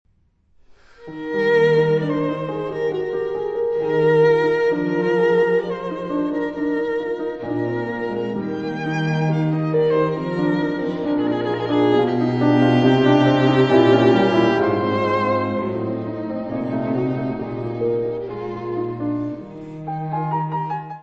Área:  Música Clássica